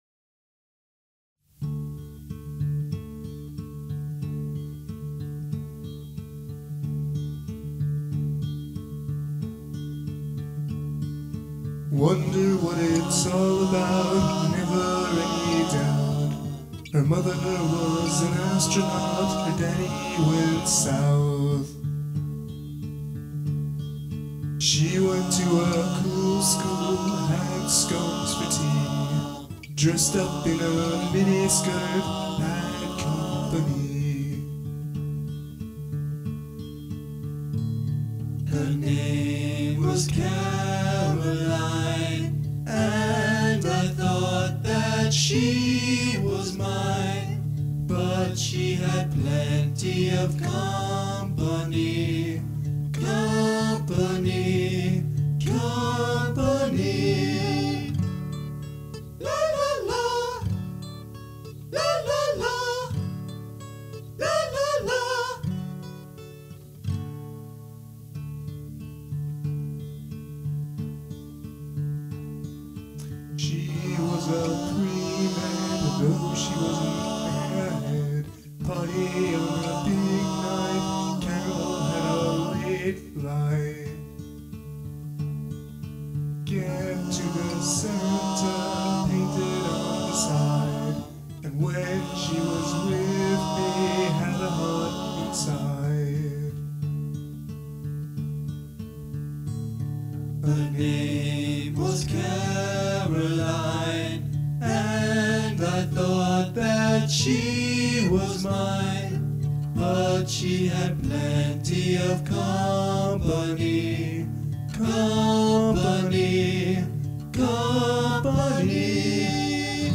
percussion
bass guitar
keyboards
guitar, vocals